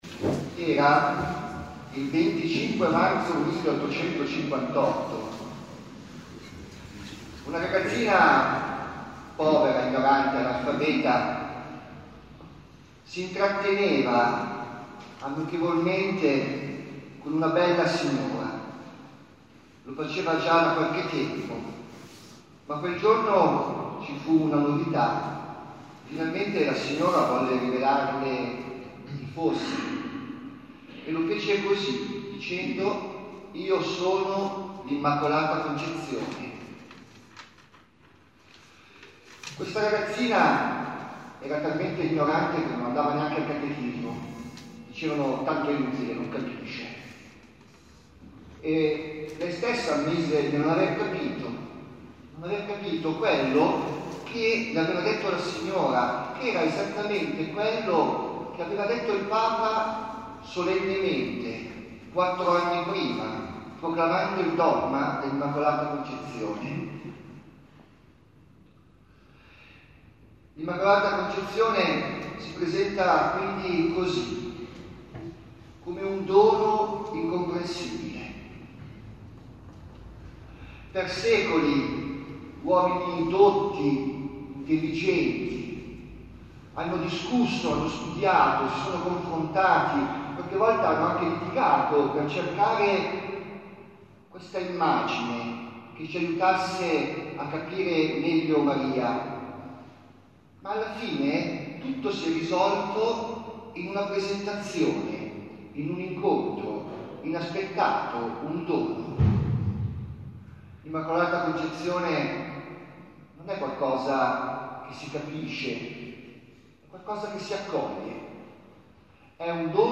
Categoria: Omelie